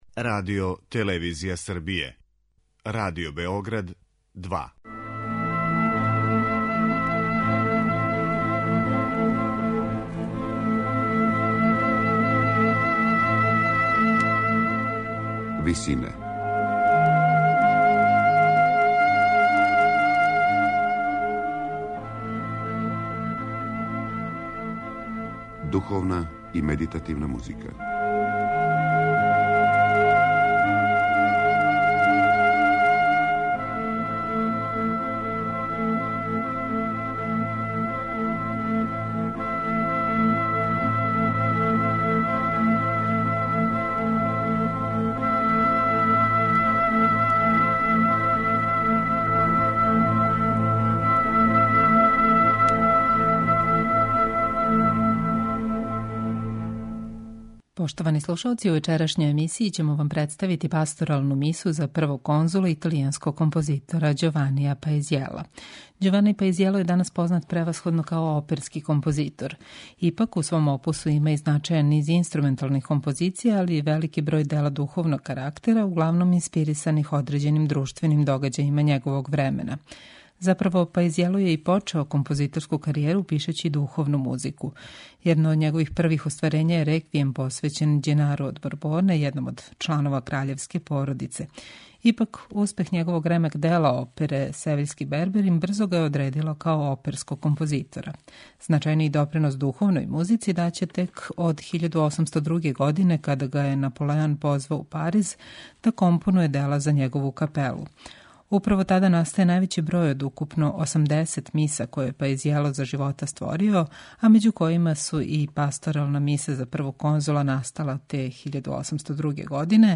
Емисија духовне и медитативне музике